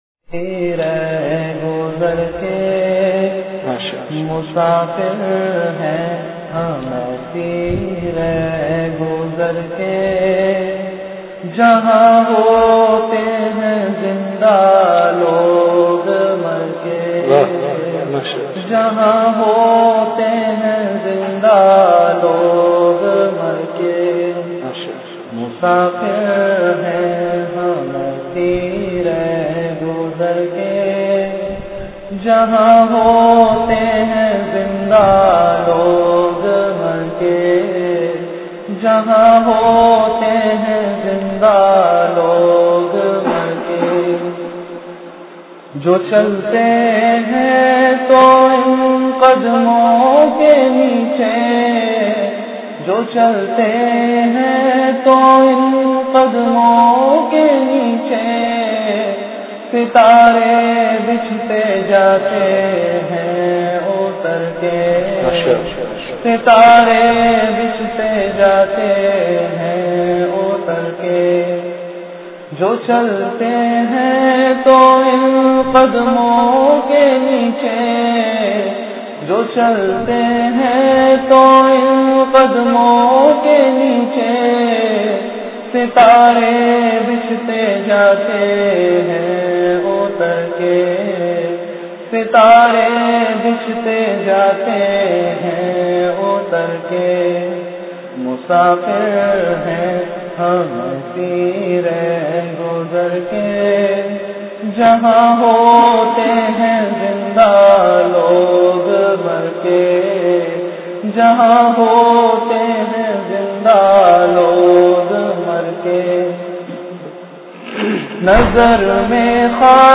Majlis-e-Zikr
After Isha Prayer